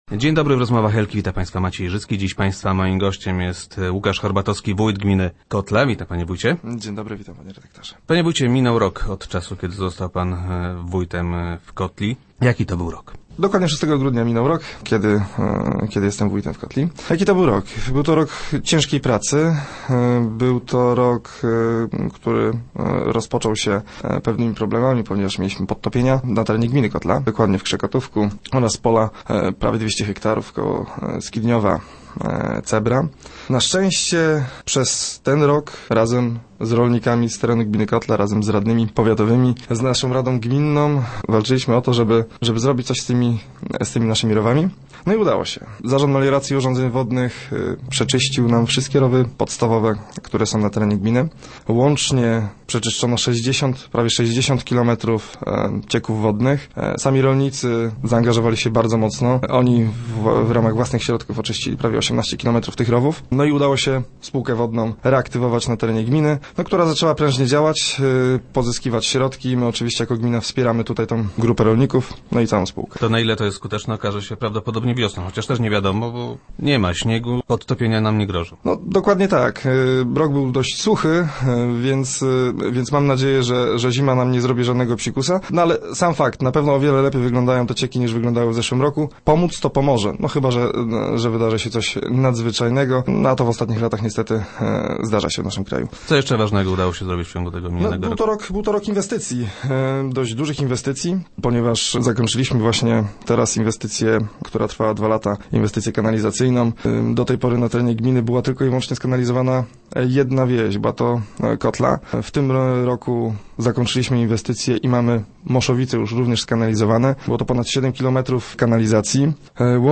Łukasz Horbatowski był gościem poniedziałkowych Rozmów Elki.